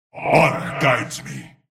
Compare the ferocious growl of the original zealot to the more human sounding voice from the sequel.
Zealot_Yes00.mp3